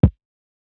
KICK LOW END III.wav